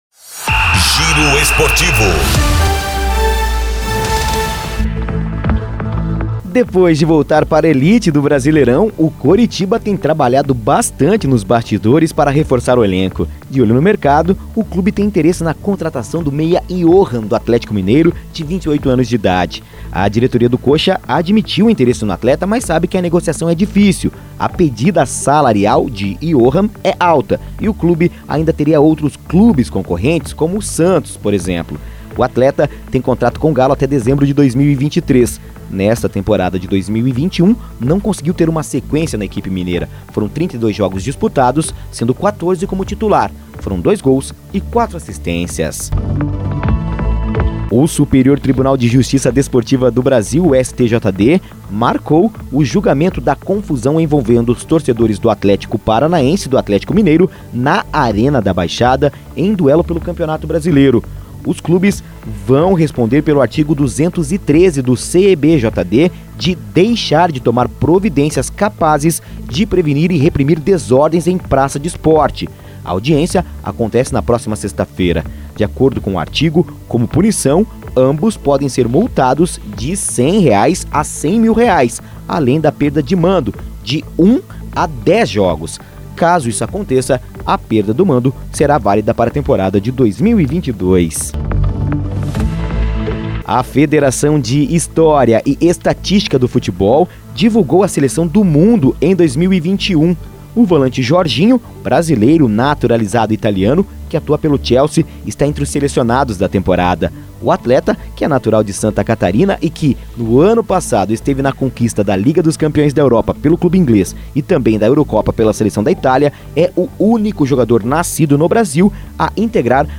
Giro Esportivo (COM TRILHA)